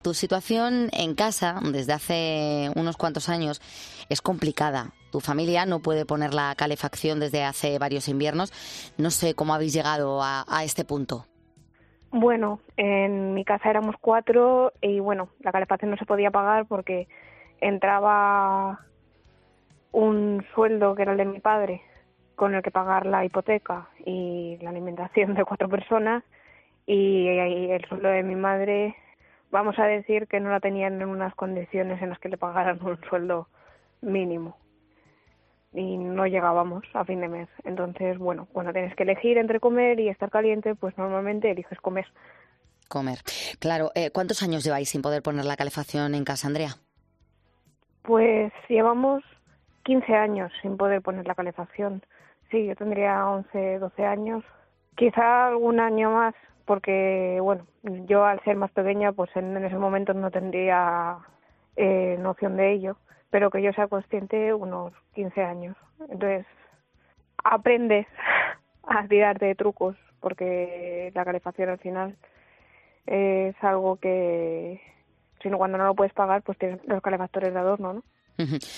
En el programa de este martes, una joven de 27 años que lo sufre dio consejos para afrontar el problema.